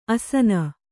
♪ asana